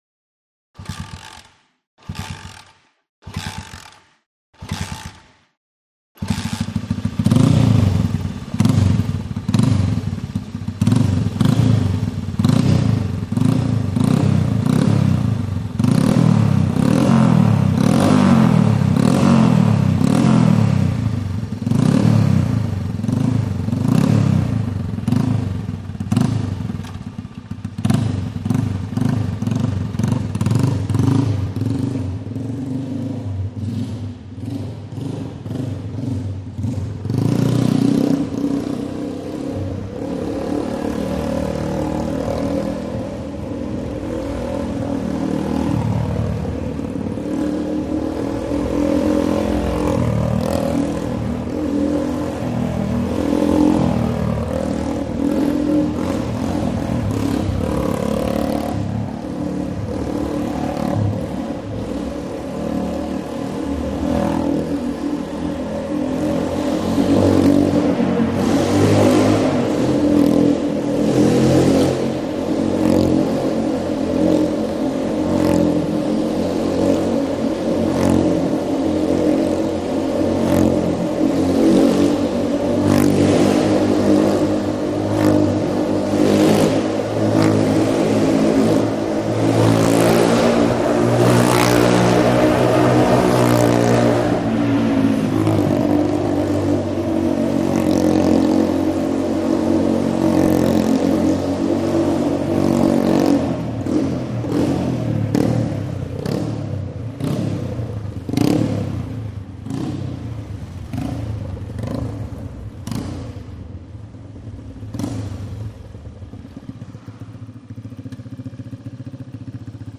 Motorcycle; Start / Revs / Away; Motorbike Start Up, Revs, Then Goes Round Wall Of Death, Finally Slows, Stops In Mid Shot, Idles And Switch Off.